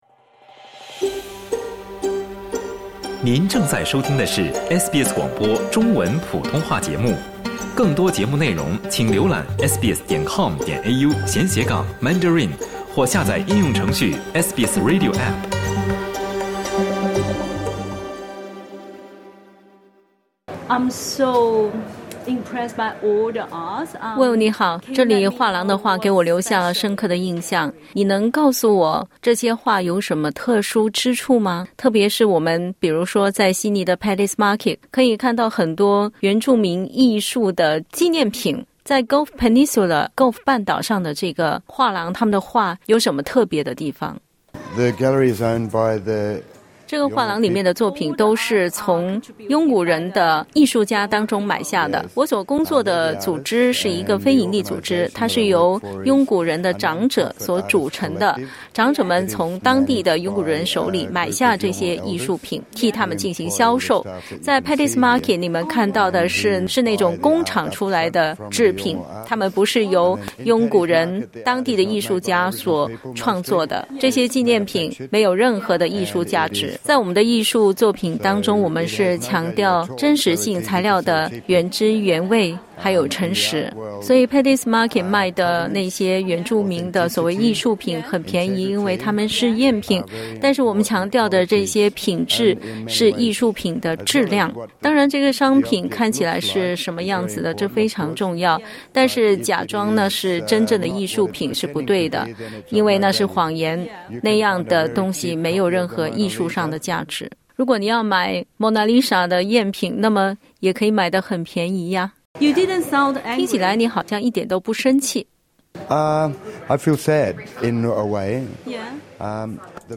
（点击图片收听完整采访） 2022的一份初步报告显示，超过60%的原住民纪念品，与原住民和托雷斯海峡岛民的文化无关。